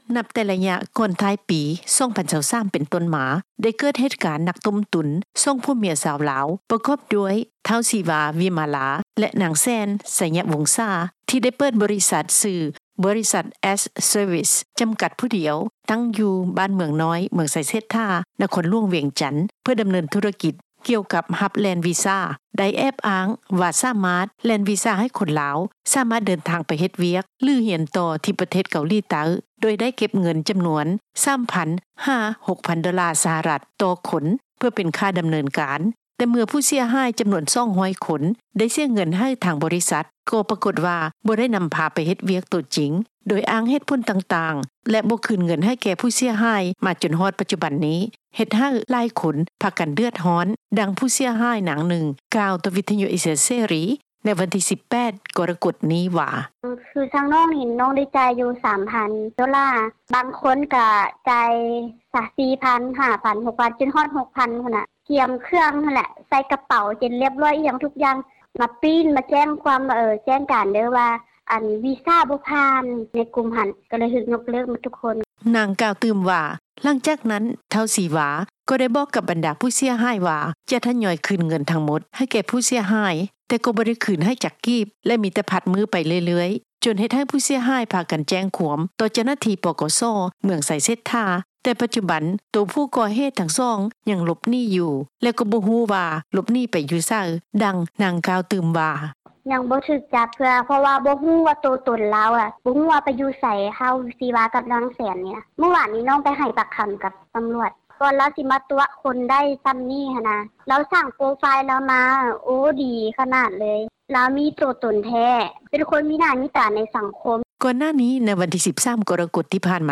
ແຕ່ເຖິງຢ່າງໃດກໍຕາມ ເຈົ້າໜ້າທີ່ແຮງງານ ແລະ ສວັດດີການສັງຄົມ ທ່ານນຶ່ງ ກ່າວວ່າ ທ່ານເອງກໍຍັງບໍ່ໄດ້ພົວພັນ ກັບ ກຸ່ມຜູ້ເສຍຫາຍ ທີ່ຖືກຕົວະໄປເຮັດວຽກດັ່ງກ່າວເທື່ອ ແຕ່ຕາມຫລັກການແລ້ວ ບັນດາຜູ້ເສຍຫາຍ ຕ້ອງໄດ້ພາກັນແຈ້ງຄວາມ ເພື່ອຟ້ອງຮ້ອງ ຕໍ່ຜູ້ກະທໍາຜິດ, ດັ່ງ ທ່ານກ່າວວ່າ:
ໃນຂະນະທີ່ເຈົ້າໜ້າທີ່ ຈັດຫາງານ ນາງນຶ່ງ ກ່າວໃຫ້ຄວາມຮູ້ແກ່ ຄົນງານລາວ ທີ່ສະແຫວງຫາໂອກາດ ໄປເຮັດວຽກ ຢູ່ເກົາຫລີໃຕ້ ວ່າ ຕາມປົກກະຕິແລ້ວ ບໍ່ມີບໍລິສັດໃດ ທີ່ເກັບເງິນຄ່າດໍາເນີນການໄປກ່ອນ ເຊິ່ງຖ້າມີການຮຽກເກັບເງິນກ່ອນ ກໍມີໂອກາດສູງ ທີ່ຈະຖືກຕົວະ ໂດຍສໍາລັບບໍລິສັດ ທີ່ຈັດຫາຄົນງານລາວໄປເກົາຫລີໃຕ້ ຢ່າງຖືກຕ້ອງ ສ່ວນໃຫຍ່ຈະເຮັດຕາມຂັ້ນຕອນ ແລະ ມີການເວົ້າເຖິງ ເລື້ອງຄ່າໃຊ້ຈ່າຍກ່ອນ ທີ່ຈະມີການເດີນທາງແທ້ໆ ດັ່ງ ນາງກ່າວວ່າ: